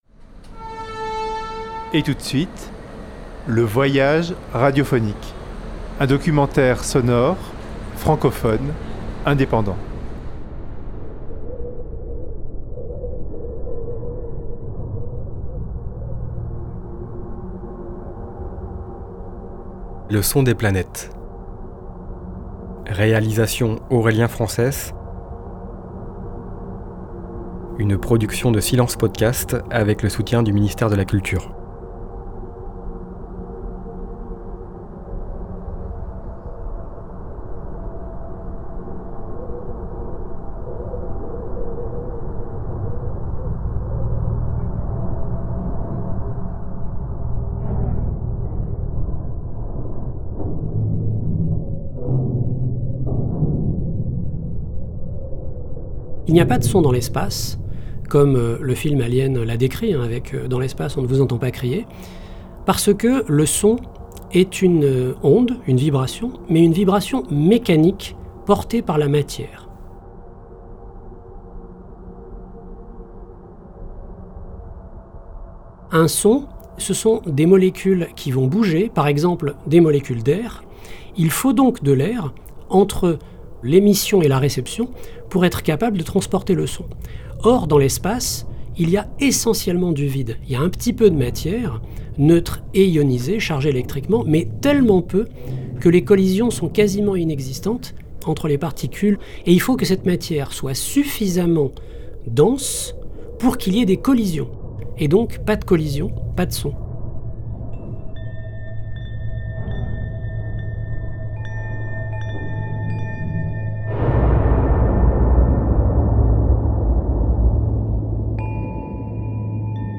S5 - LE SON DES PLANETES.mp3